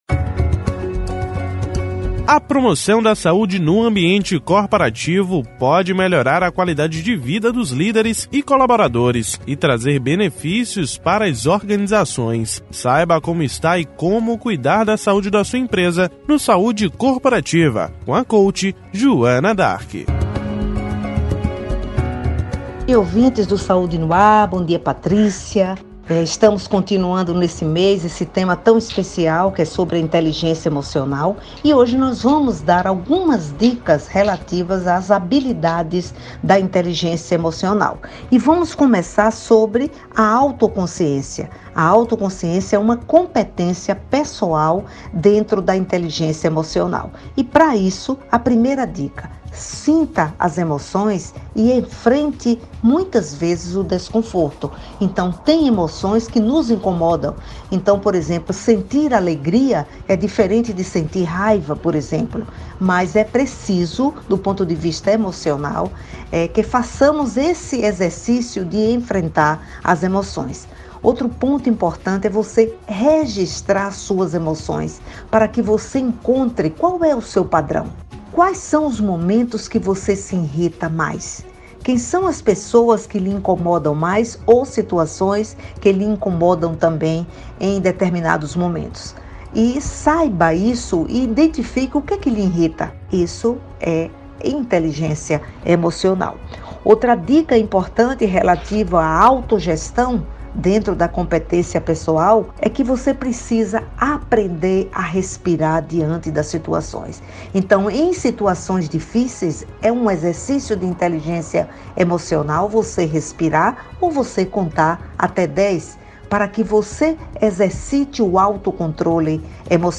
Ouça o comentário com as dicas da especialista e entenda mais sobre a inteligência emocional, no áudio abaixo: